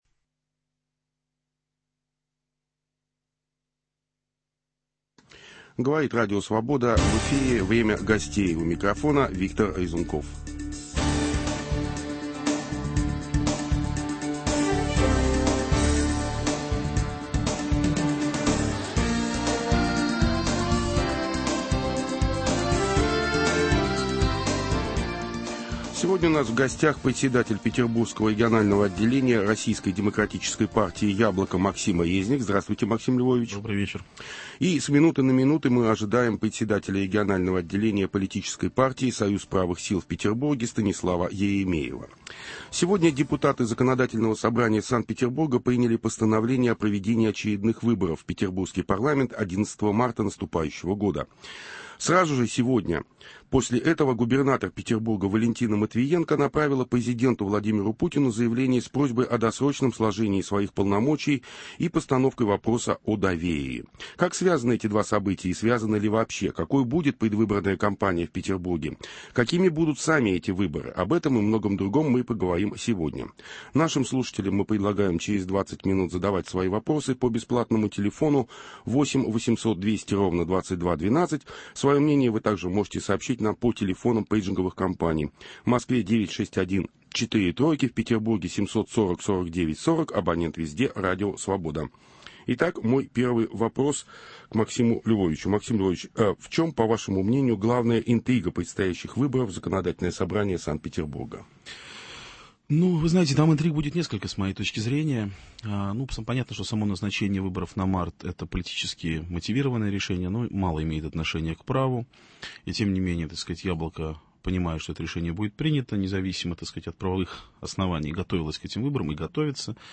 Какими видятся предстоящие выборы лидерам правой оппозиции в Петербурге? В гостях - председатель Петербургского регионального отделения Российской Демократической Партии "Яблоко" Максим Резник.